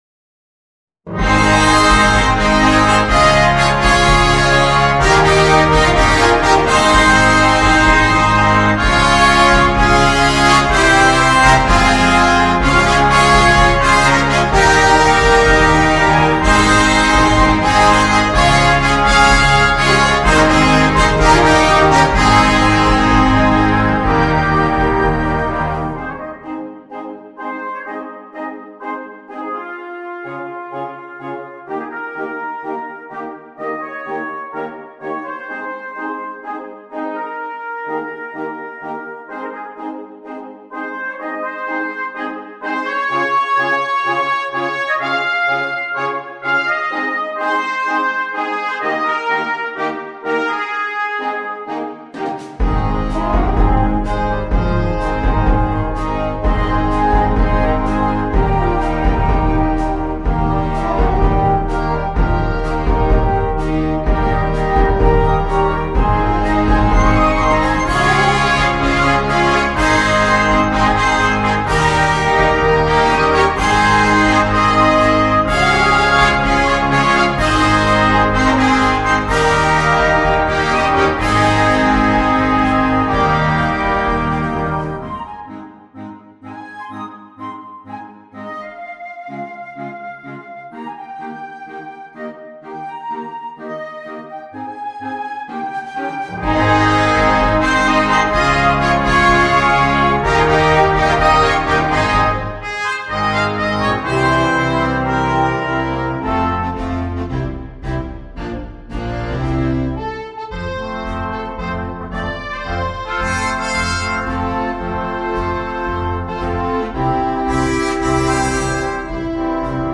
2. Concert Band
Full Band
without solo instrument
Entertainment
Music Sample